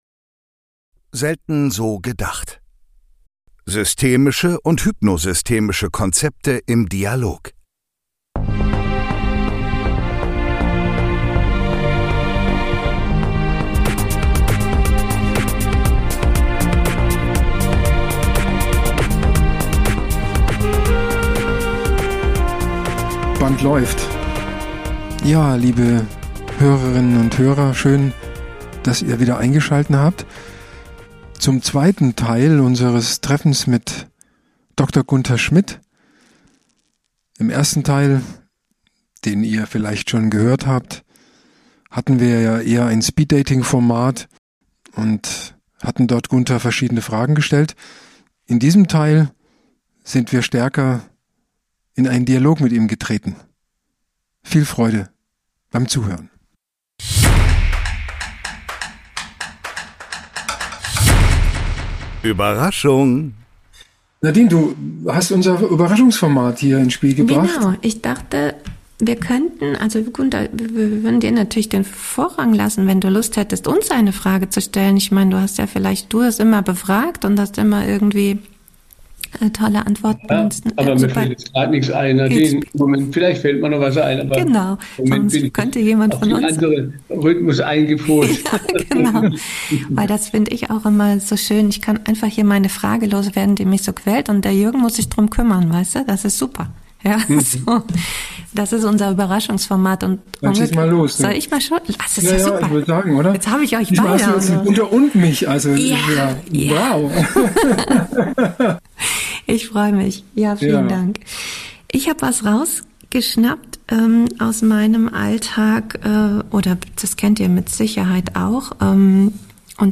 Ein Dialog im Überraschungsformat